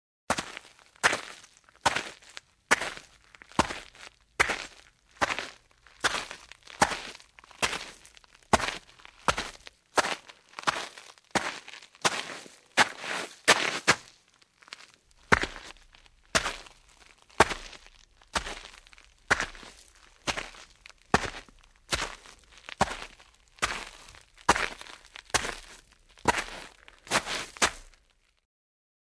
Звуки ходьбы, шагов
Шаги по гравию
Иду по гравию